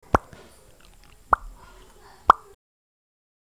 Download Pop Up sound effect for free.
Pop Up